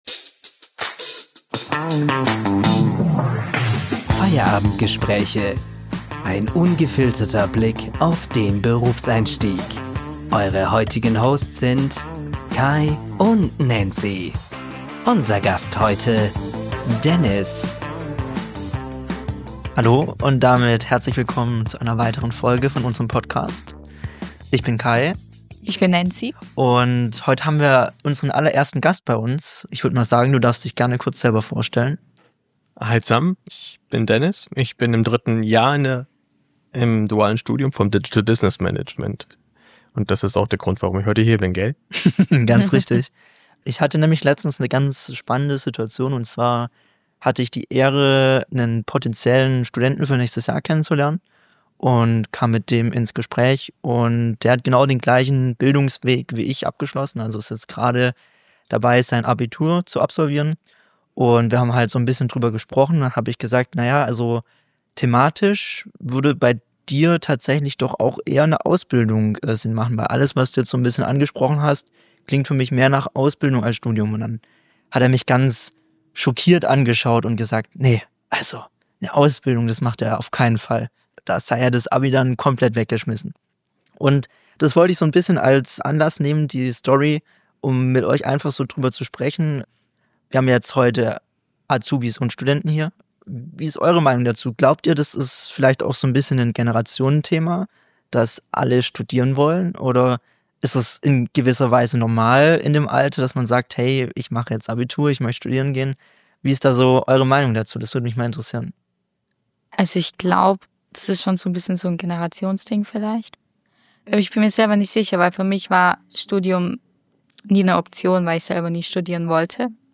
Beschreibung vor 1 Jahr Willkommen zur zweiten Folge von Feierabend-Gespräche!